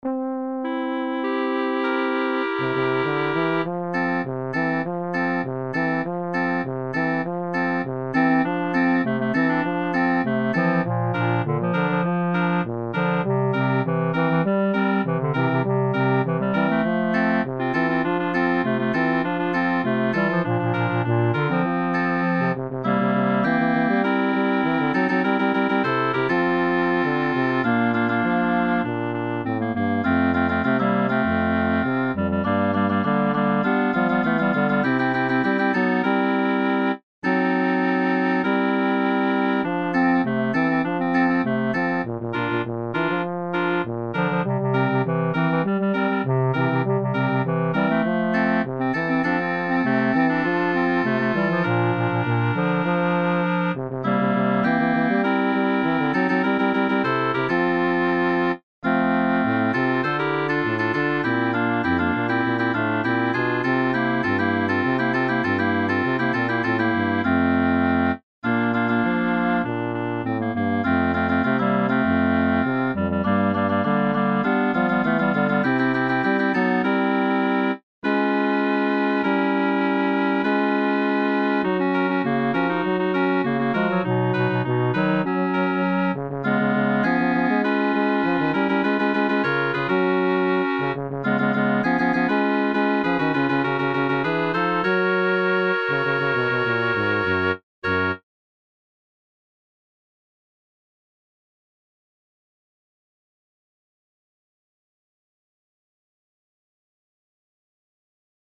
Midi Preview